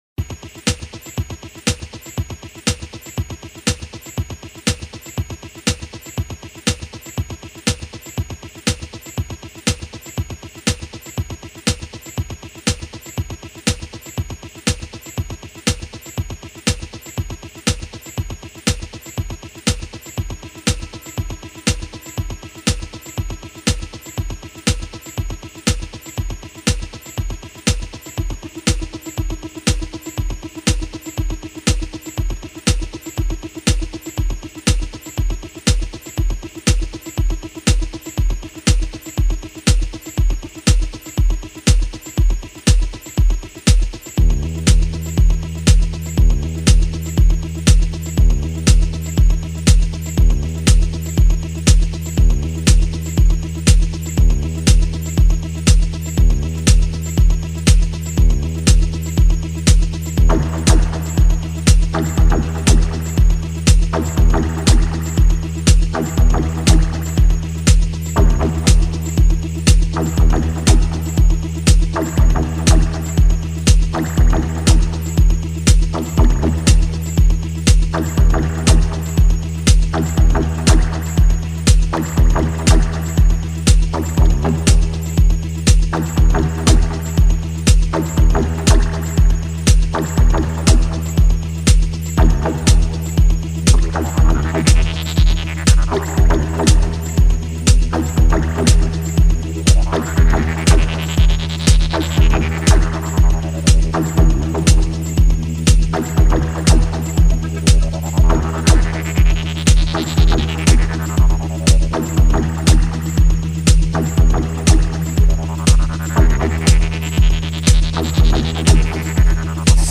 dúo de música electrónica
house, disco y techno